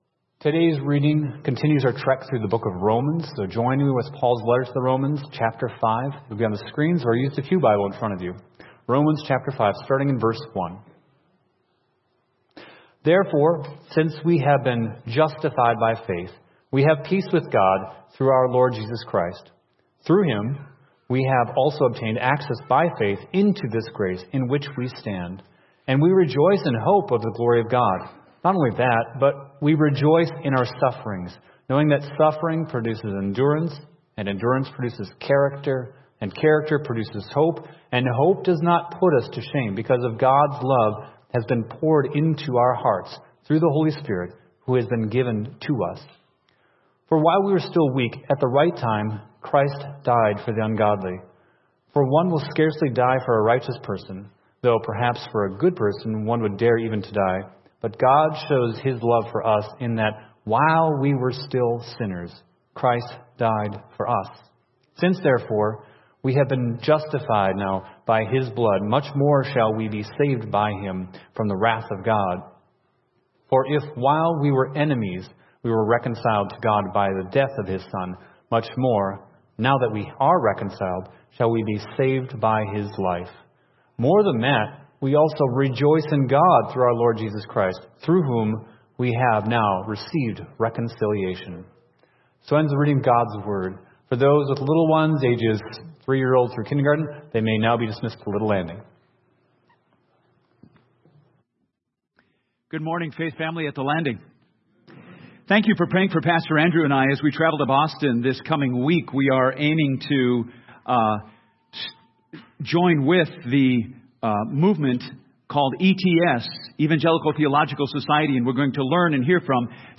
Sermons | The Landing Church